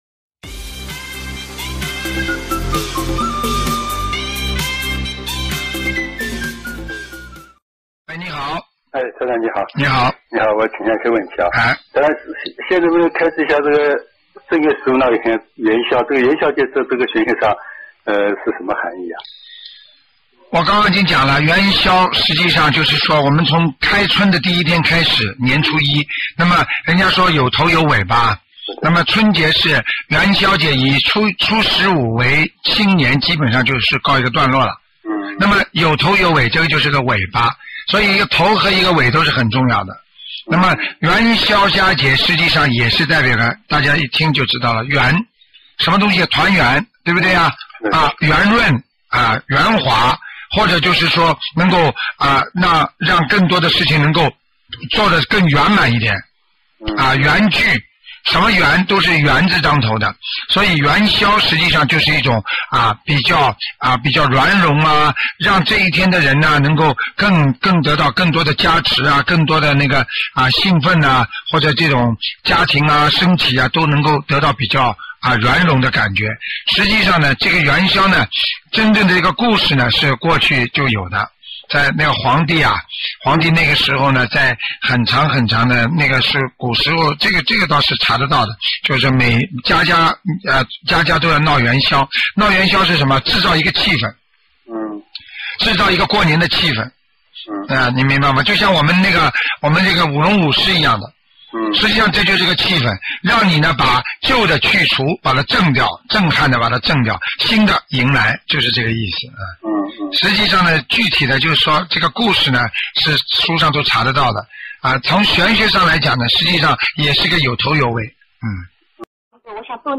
音频：师父开示_元宵节应该怎么过？需要特别注意什么？